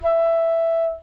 Original 4i Sound Processor flute sample, extracted from PDP-11 floppy disk.